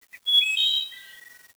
bird1.wav